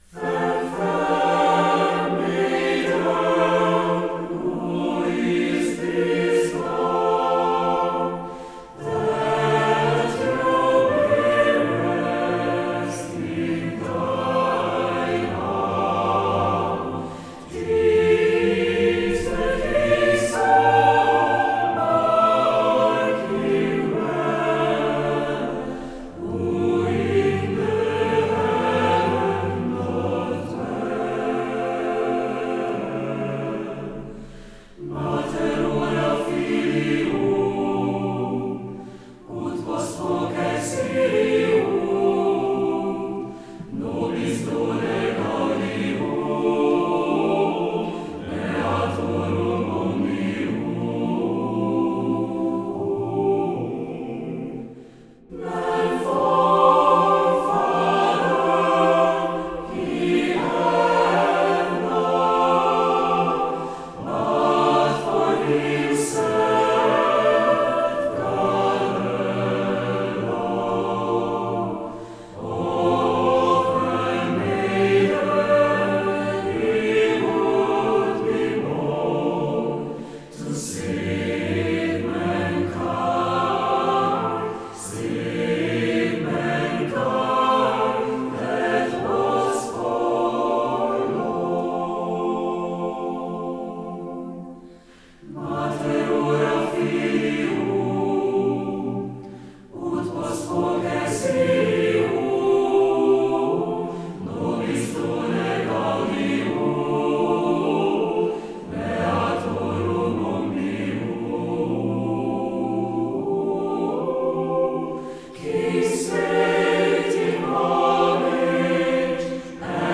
Instrumente - Mixed Choir Tempo - Medium BPM - 88